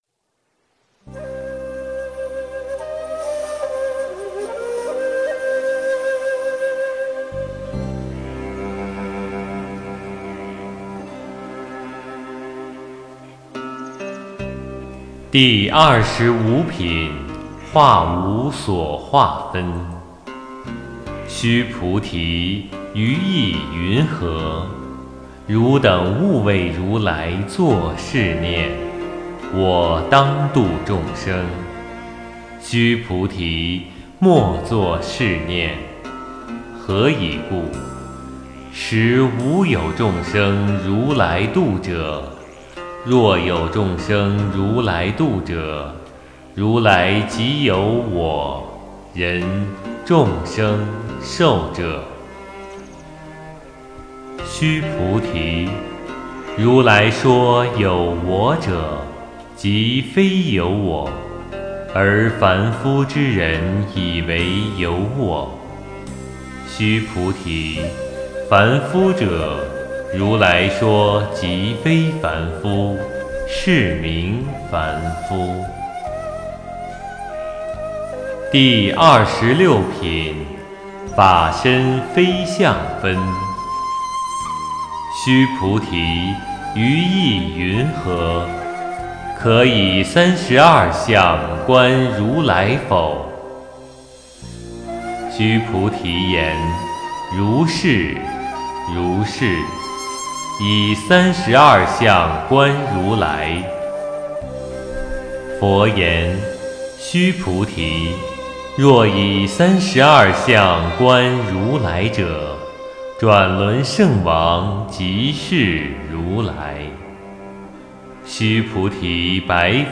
诵经
标签: 佛音诵经佛教音乐